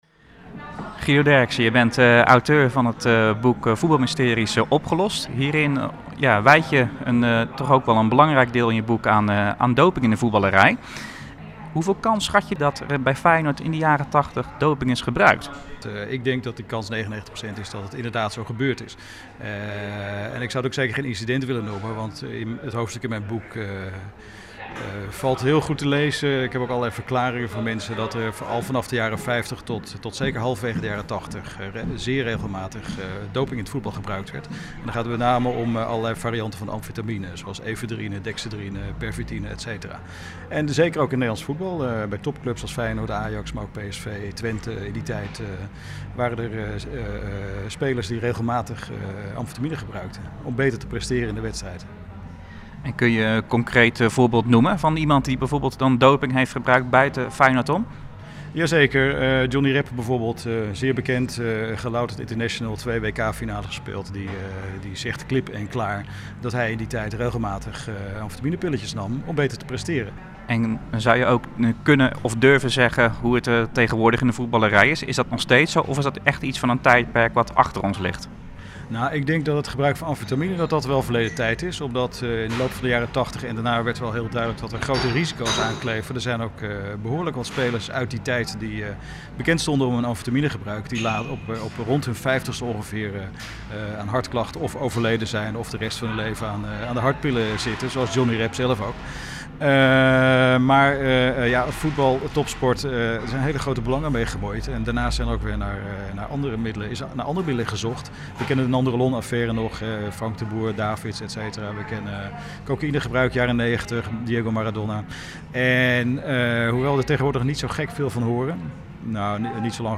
Verslaggever
in gesprek met onderzoeksjournalist